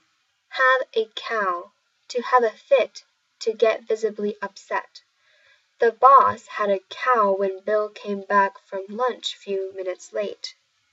英語ネイティブによる発音は以下をクリックしてください。